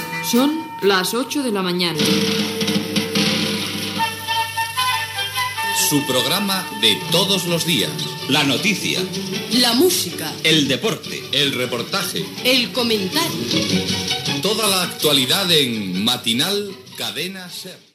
Hora i careta del programa